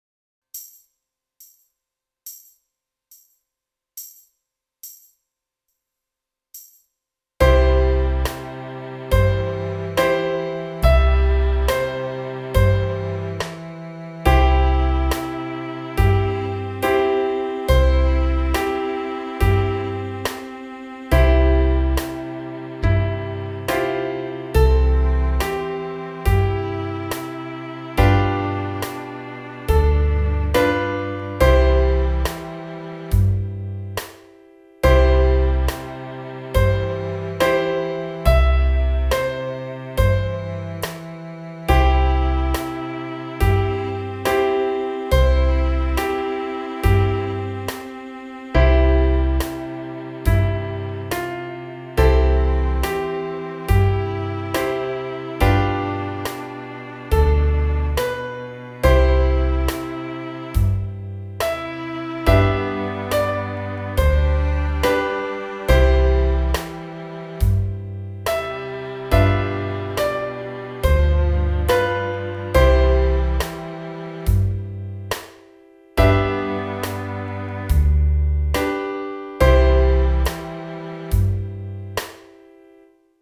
Online: MP3- Sounds der Lieder, div.